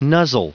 Prononciation du mot nuzzle en anglais (fichier audio)
Prononciation du mot : nuzzle